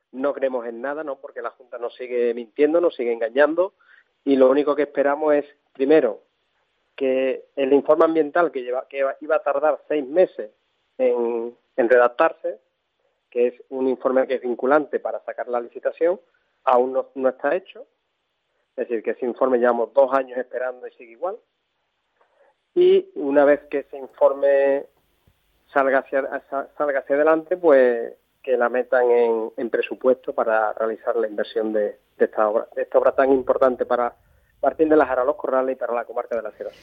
Manuel Sánchez, alcalde de Martín de la Jara
informativos